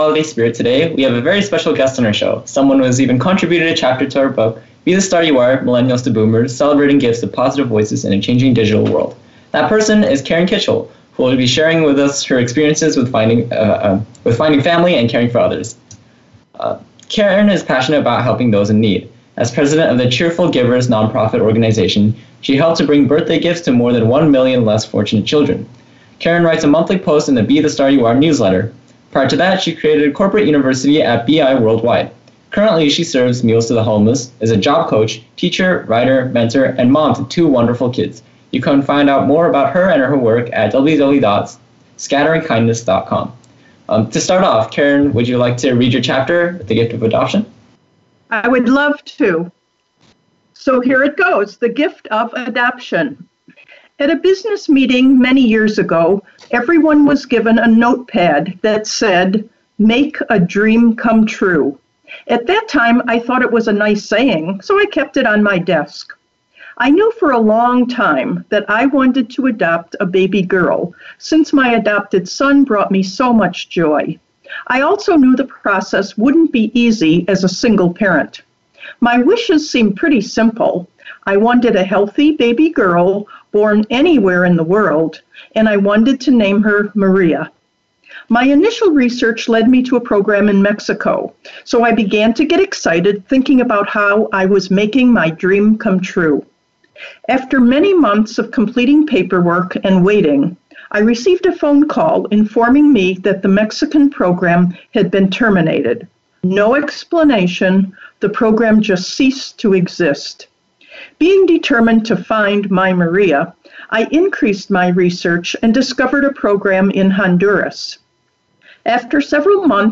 Radio interview includes the chapter I wrote for a recently published book on “The Gift Of Adoption.”